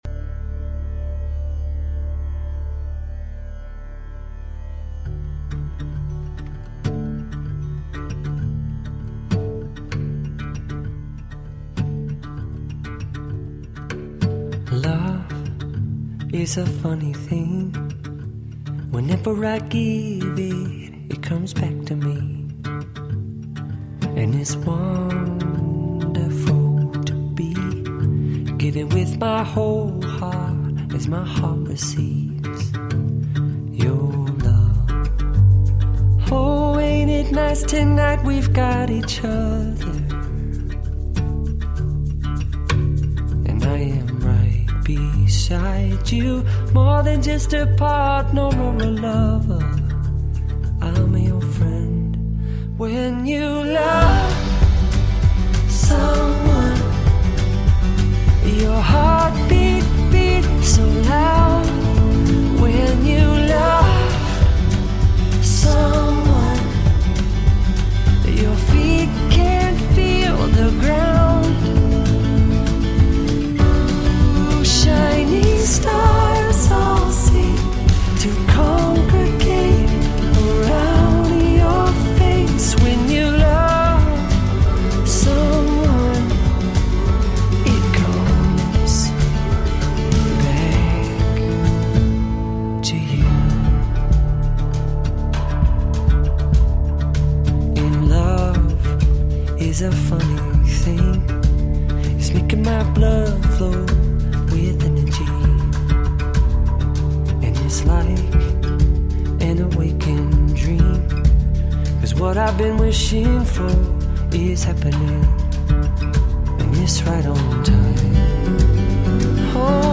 Жанр: Pop Rock / Acoustic